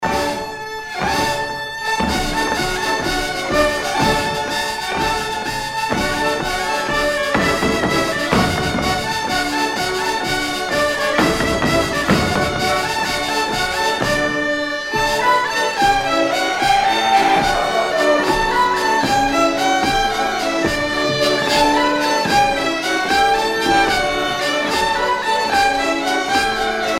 Enfantines - rondes et jeux
Pièce musicale éditée